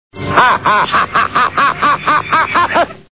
ha_ha.wav